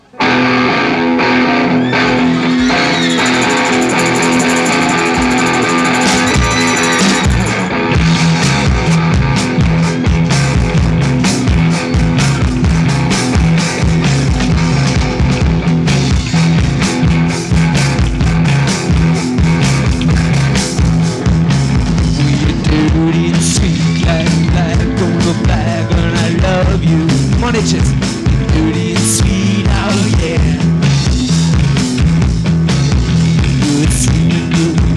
Жанр: Поп музыка / Рок
Glam Rock, Rock, Pop